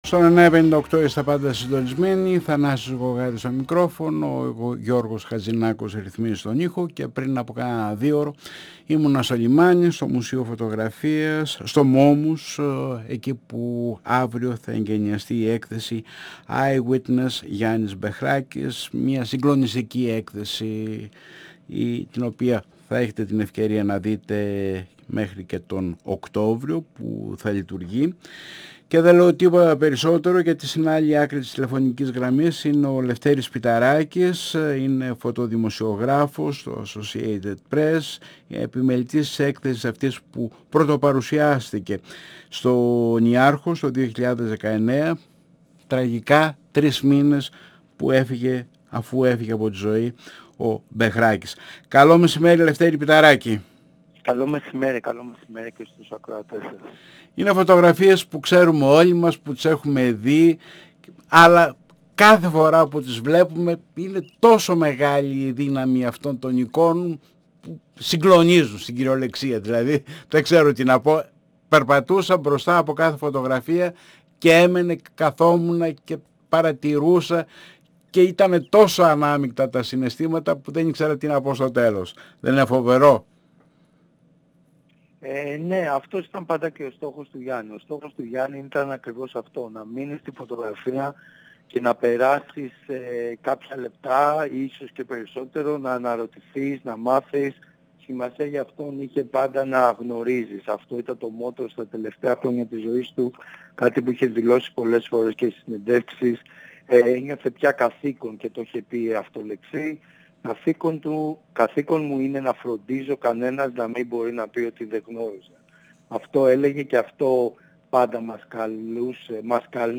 958FM Συνεντεύξεις